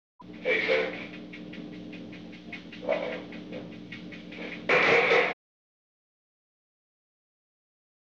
Secret White House Tapes
Conversation No. 369-8
Location: Executive Office Building
The President talked with an unknown person.